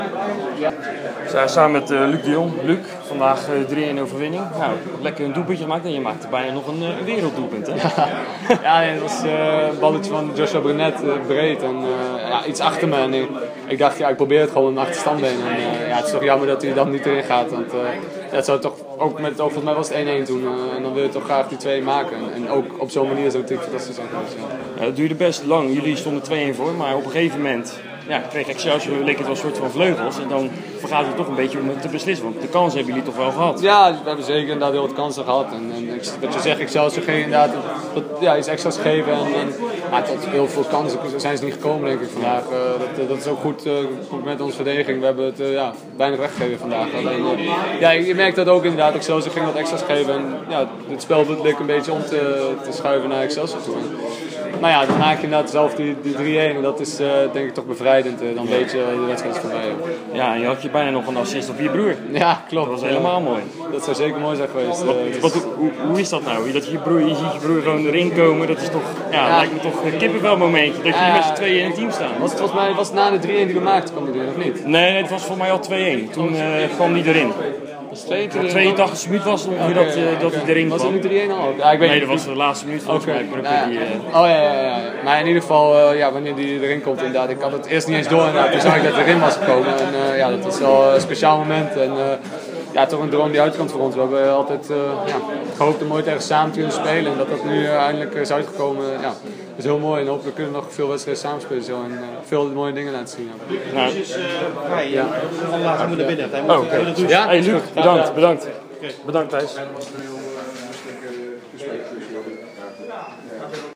sprak met de aanvoerder: